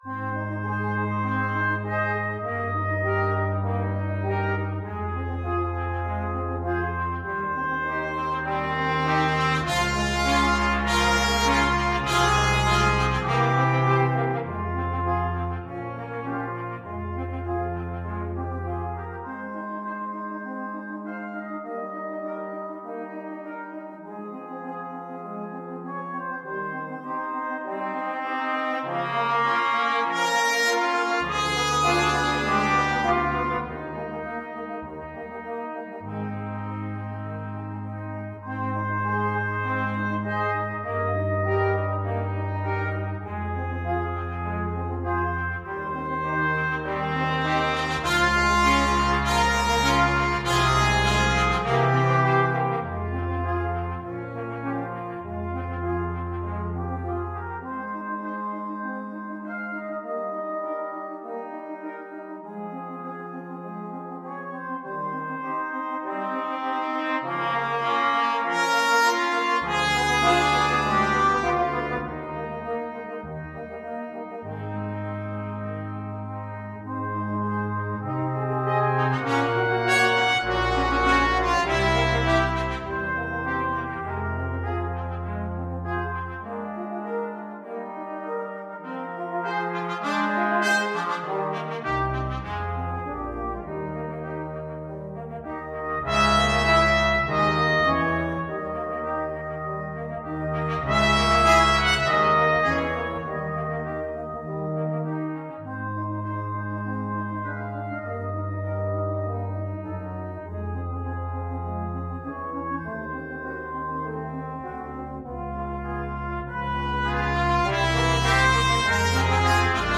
Classical Dvořák, Antonín Four Romanic Pieces, Op.75, No.1 Brass Quintet version
Free Sheet music for Brass Quintet
Trumpet 1Trumpet 2French HornTromboneTuba
4/4 (View more 4/4 Music)
Ab major (Sounding Pitch) (View more Ab major Music for Brass Quintet )
Allegro moderato (View more music marked Allegro)
Classical (View more Classical Brass Quintet Music)
dvorak_4_romantic_pieces_op75_1_BRQN.mp3